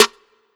Snares
Divine Feminine Snare.wav